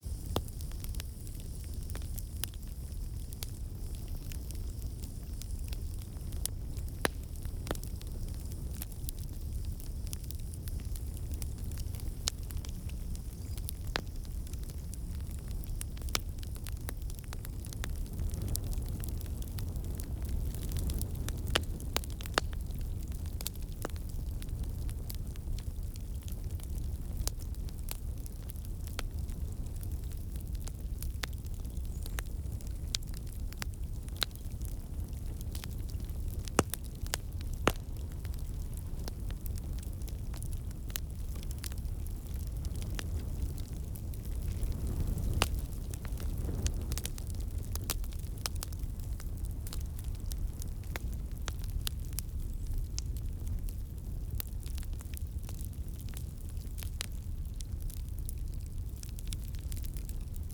daytimeForrestBonfire.mp3